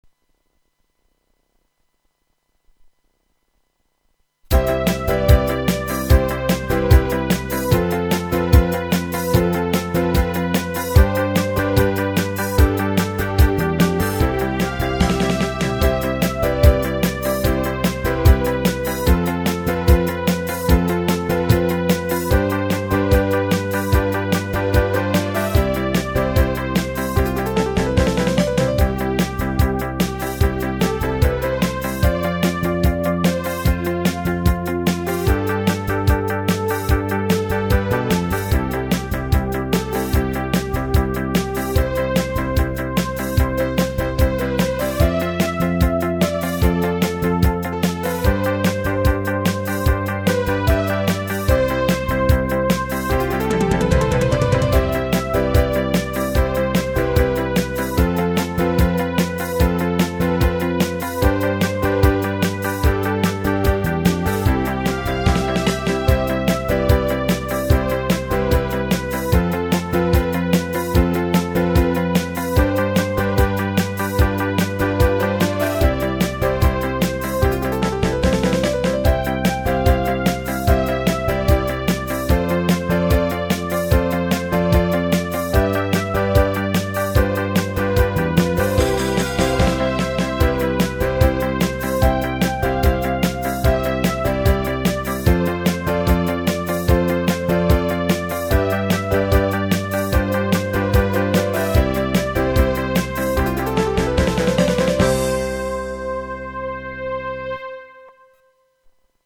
MIDI音源は、機種の互換性に乏しいので、使えるサーバの容量にゆとりが出てきたので、MP3ファイルを置くことにしました、演奏はローランドSC-88Proにより行い演奏と録音のマルチタスクで作業を行い。
GM音源用にリメイクしました。使用しているコードは、Ｃ、Ｆ、Ｇ７といういわゆる、トニック、サブドミナント、ドミナントというもっとも基本的なコードだけです。